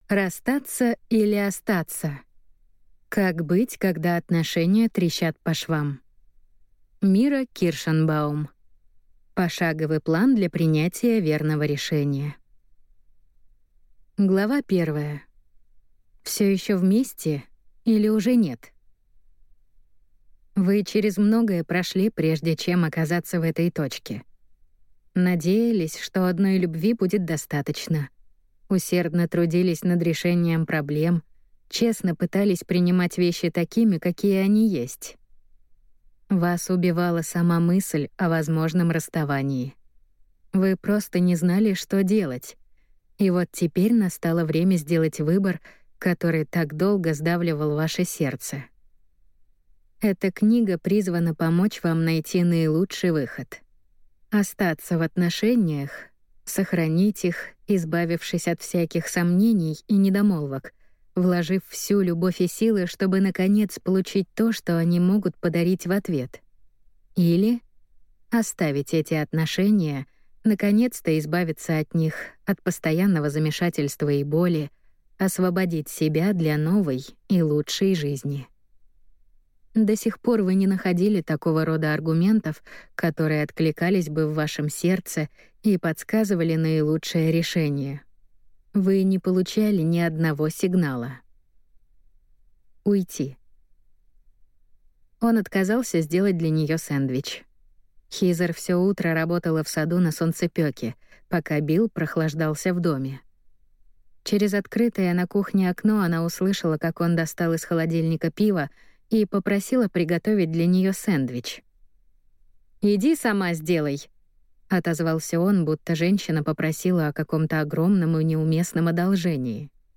Аудиокнига Расстаться или остаться? Как быть, когда отношения трещат по швам | Библиотека аудиокниг